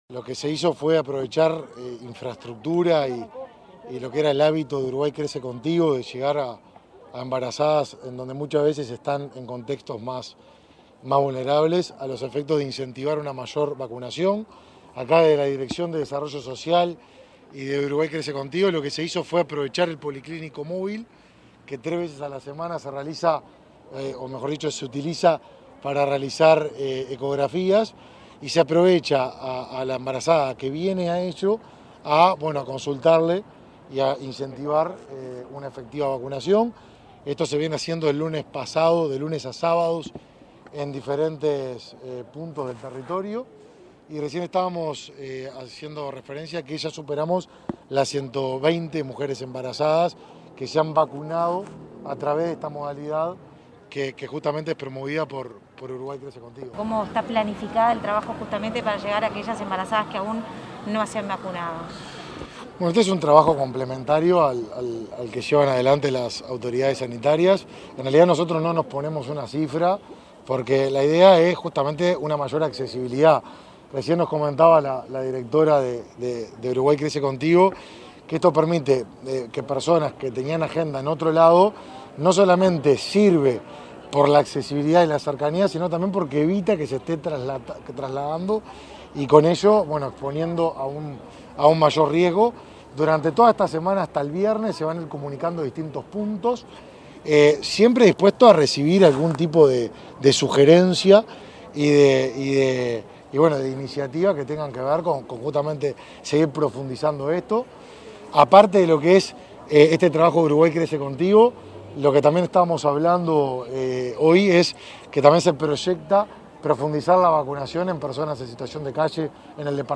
Declaraciones del ministro de Desarrollo Social, Martín Lema